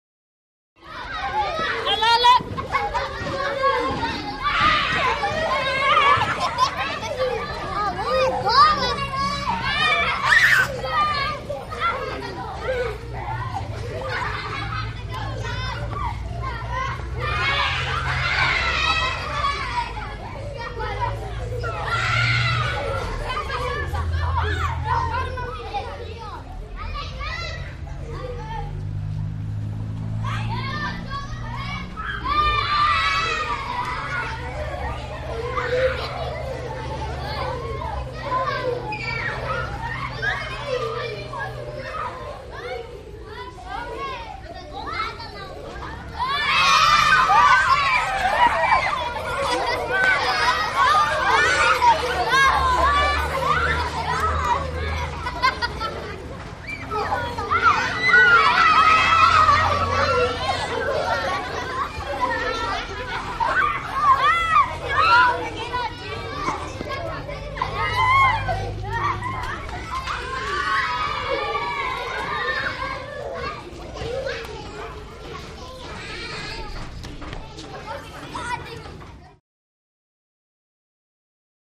Foreign Town; Brazilian Square Atmosphere. Close Children At Play With Occasional Vehicle Passing In Mid Shot.